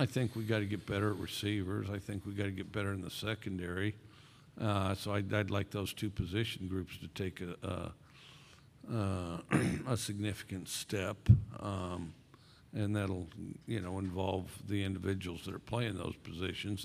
Mississippi State head football coach, Mike Leach, spoke at the 2022 SEC Media Days on Tuesday.